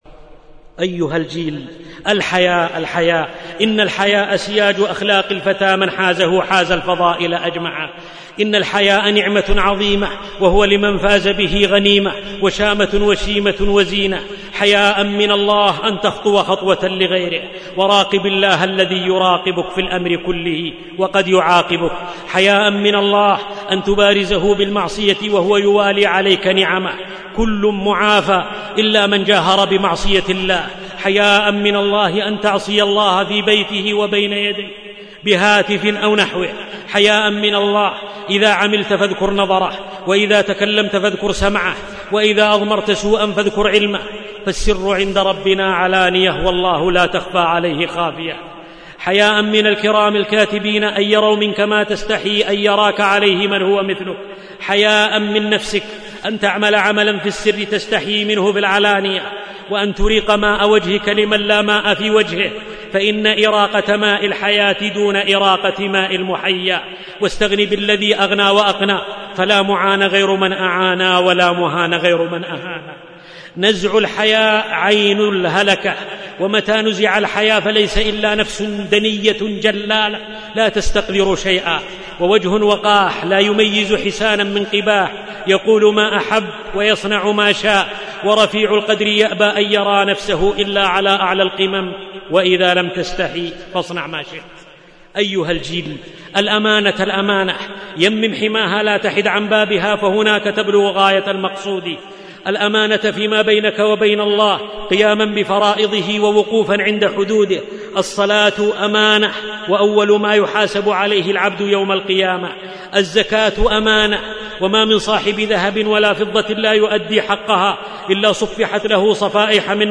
الدروس